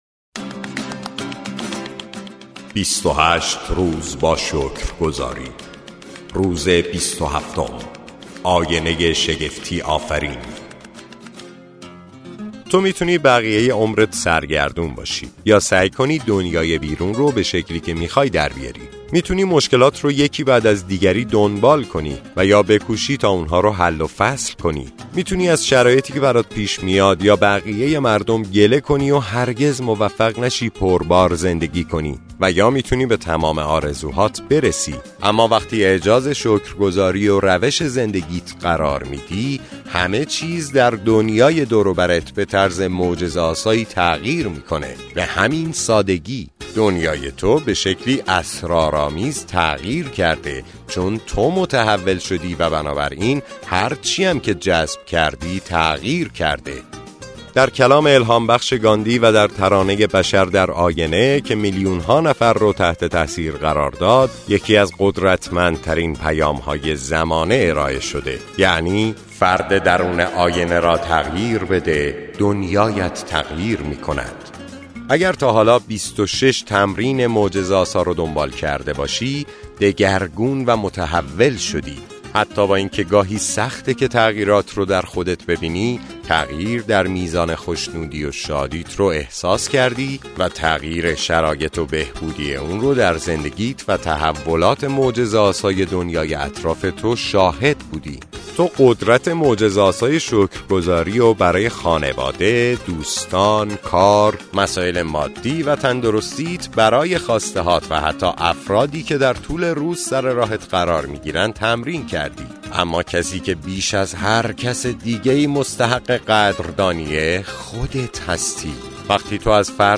کتاب صوتی معجزه شکرگزاری